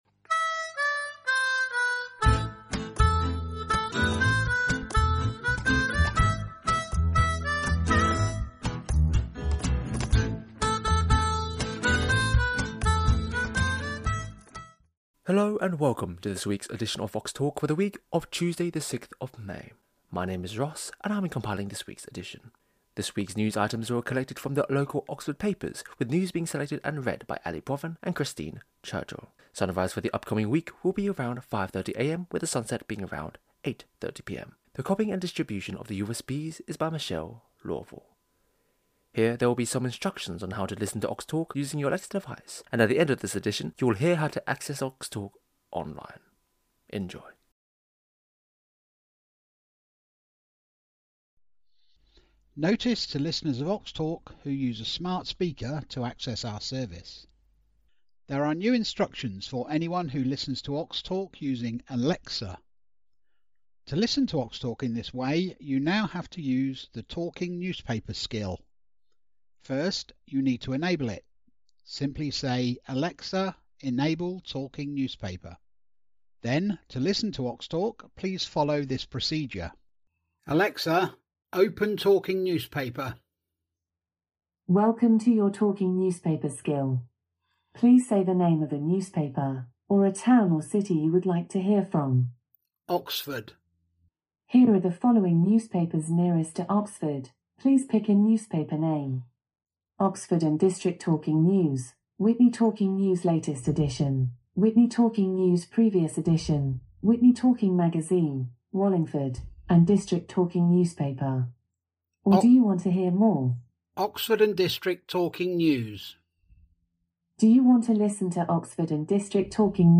6th May 2025 edition - Oxtalk - Talking newspapers for blind and visually impaired people in Oxford & district
Talking News: 6th May 2025